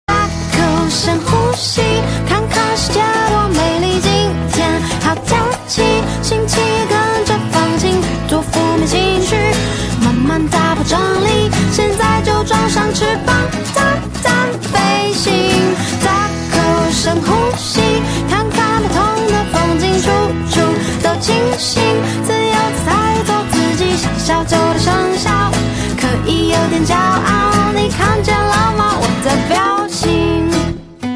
M4R铃声, MP3铃声, 华语歌曲 120 首发日期：2018-05-15 03:13 星期二